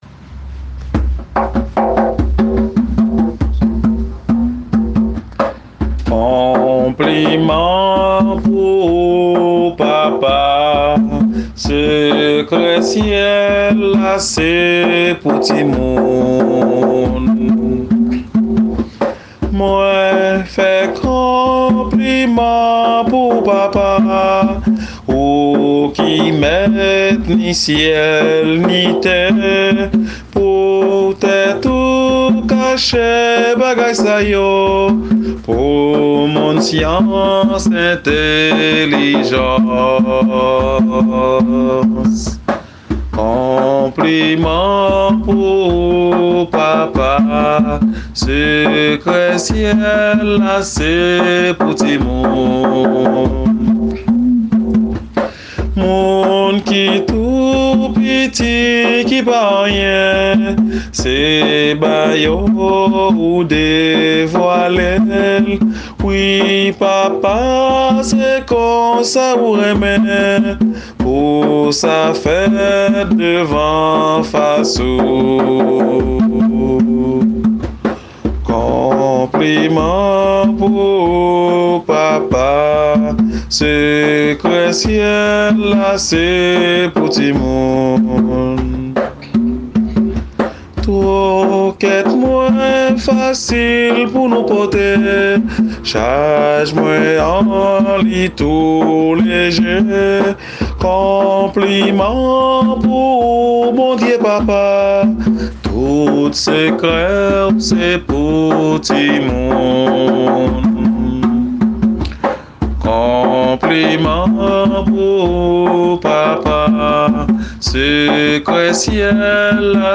Kantik Kréyòl